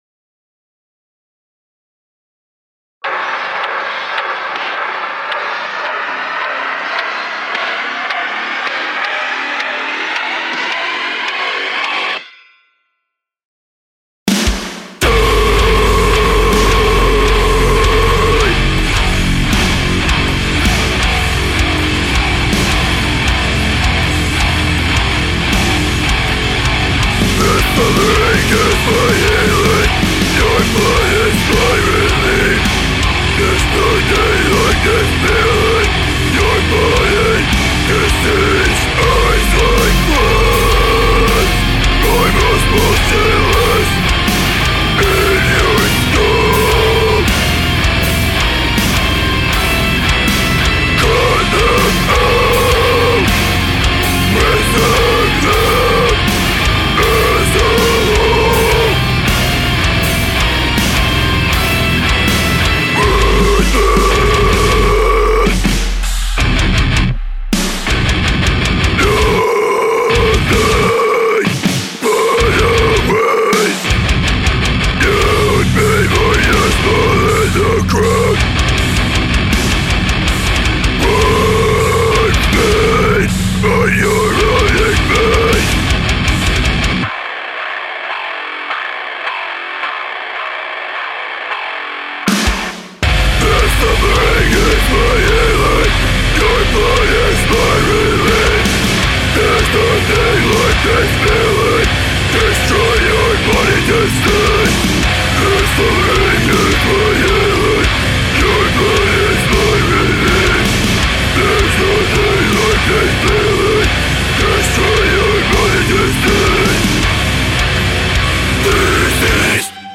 Metal mix/master